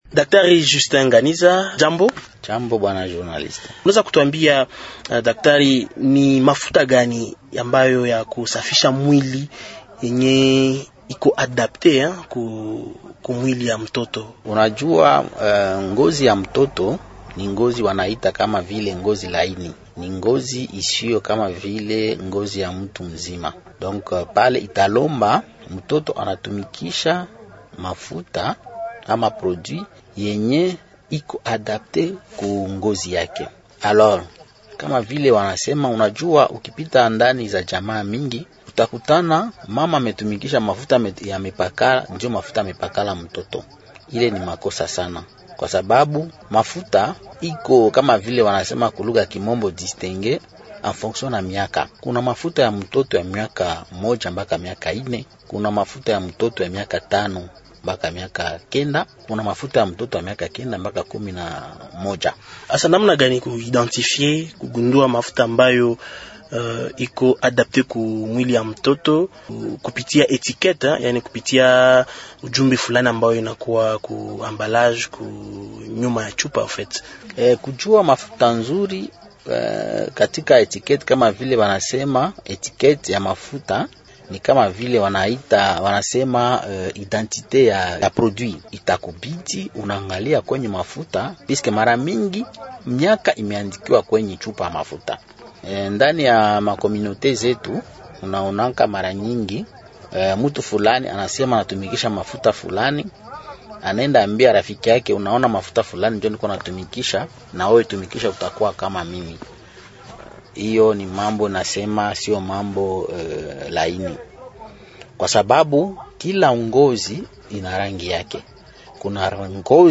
L'invité swahili, Émissions / Institut Supérieur de Management, ISM, étudiants